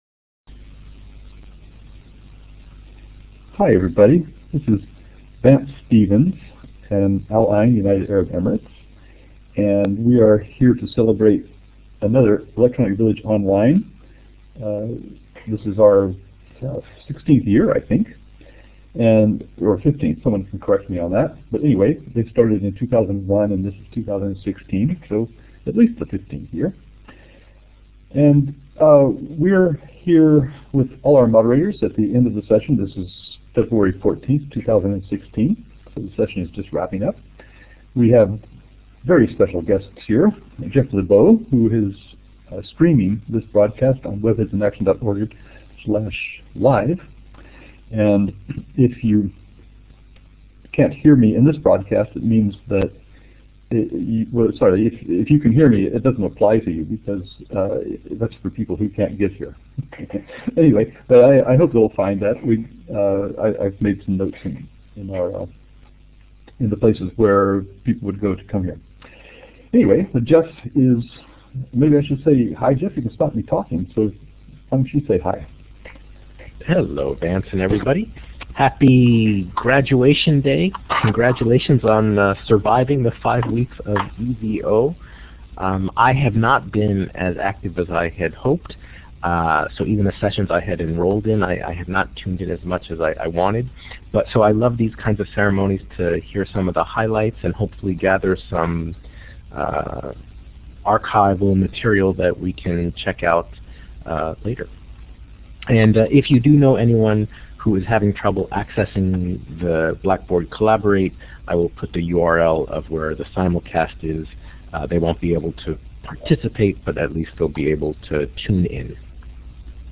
Each session gets a 5 minute space in which to explain what they did and how they did it, and all EVOMC16 moderators and participants are invited to contribute to the conversation and to the subsequent recording.